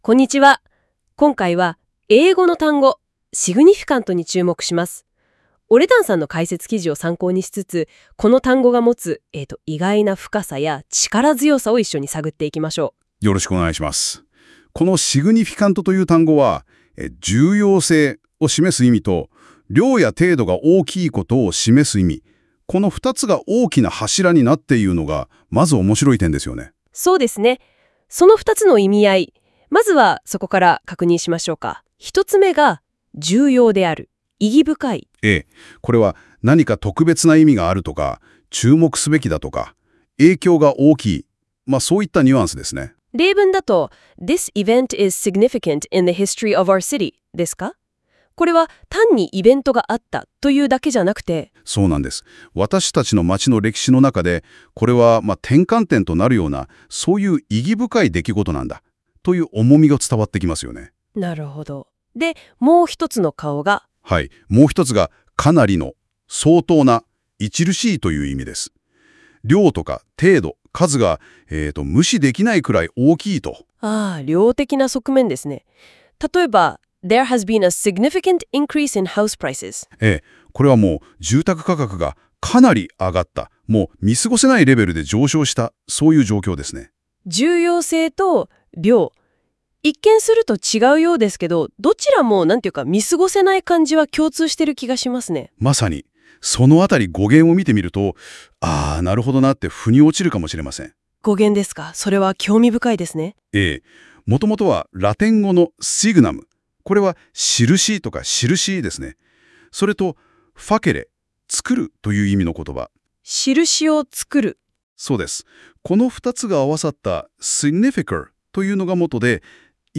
英単語Significant解説.wav